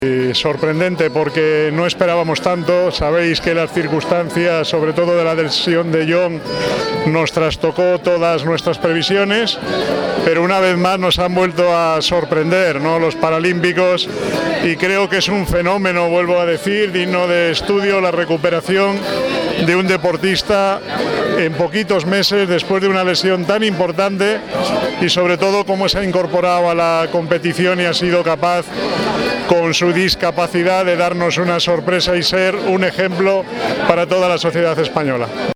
al recibir en Barajas a la delegación española (archivo MP3).